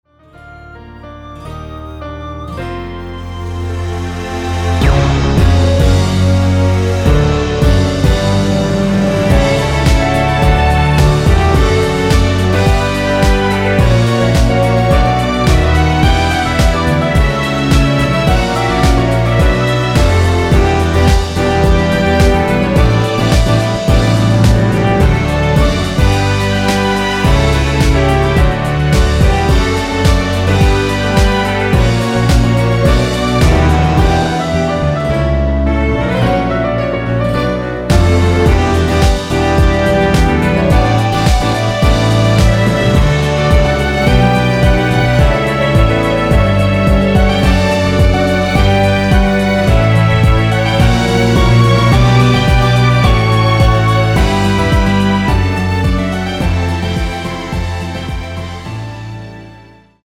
1절후 후렴구로 진행 되게 편곡 하였습니다.(가사및 미리듣기 참조)
앞부분30초, 뒷부분30초씩 편집해서 올려 드리고 있습니다.
중간에 음이 끈어지고 다시 나오는 이유는